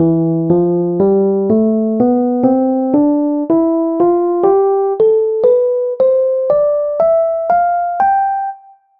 ފިގާރ 2.2: ގިޓާރގެ ނޯޓްތައް ސްޓާފްގައި ހުންނަ ތަންތަން. ނަމްބަރުތައް ރަމްޒުކޮށްދެނީ ތޫލިކަން މައްޗަށްދާ ތަރުތީބު
guitar-sounds-1.mp3